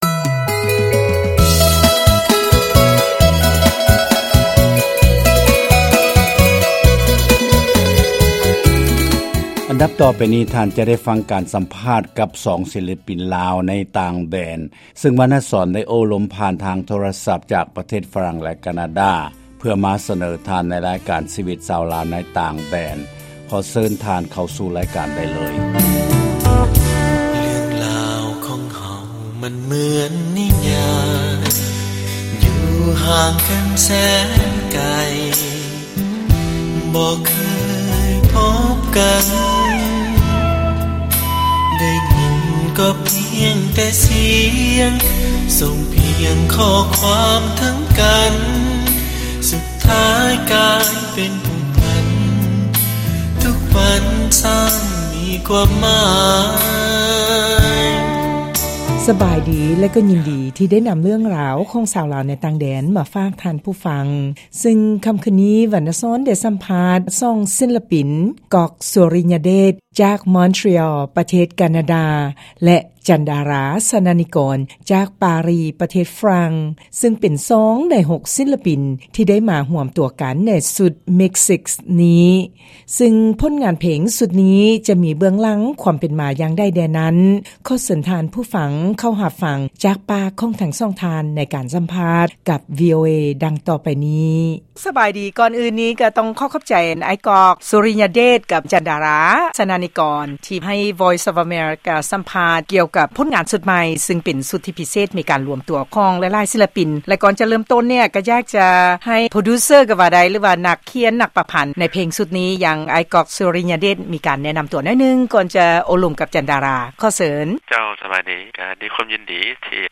ລາຍການສໍາພາດ